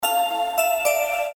• Качество: 320, Stereo
мелодичные
без слов
короткие